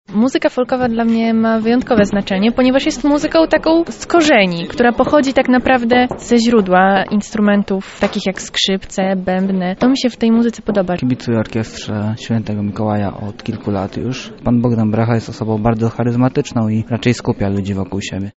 Najnowsze utwory zaprezentowano wczoraj podczas koncertu w Chatce Żaka. Wrażeniami z wydarzenia podzielili się jego uczestnicy.